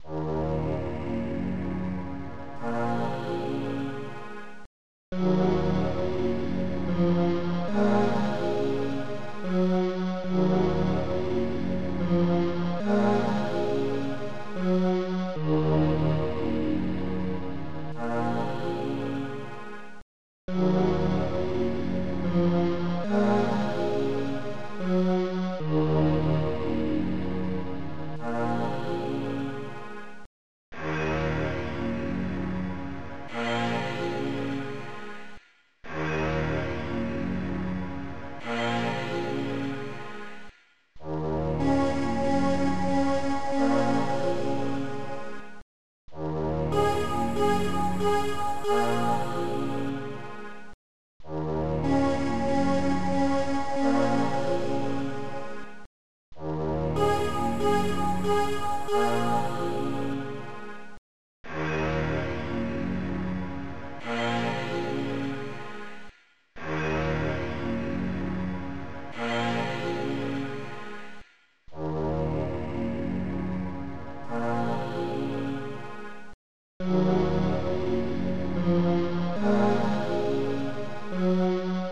Protracker Module
bass lead CYMBAL